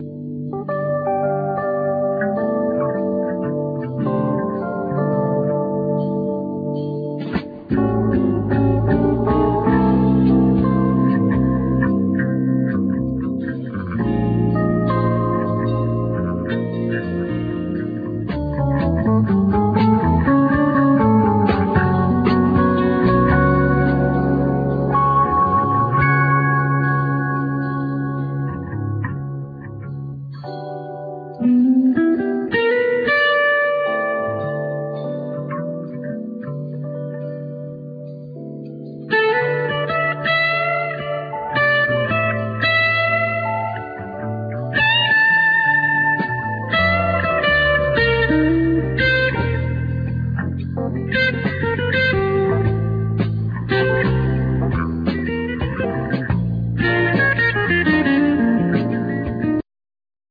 Keyboards, Fender piano
Sopranosax, Violin
Bass
Percussions
Drums
Vocal
Acoustic guitar
Guitar